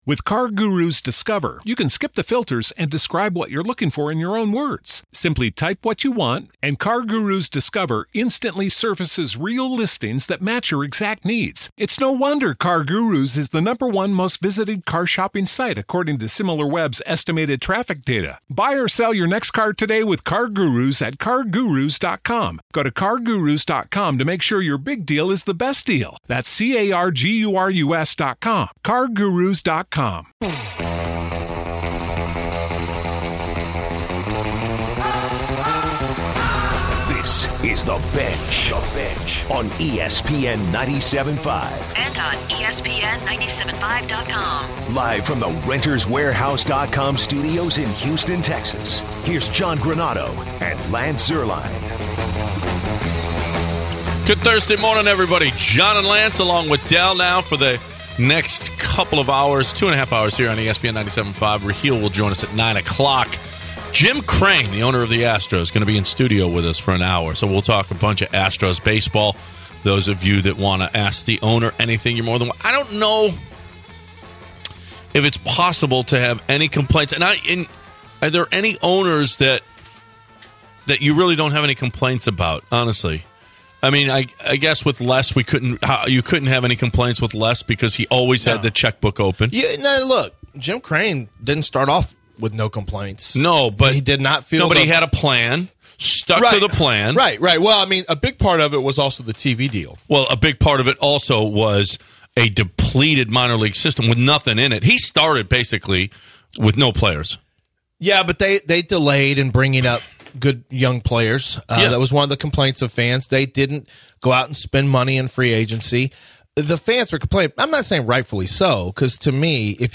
To finish out the hour, Astros Owner Jim Crane joins the guys in the studio to discuss his experience watching the Stros win the World Series.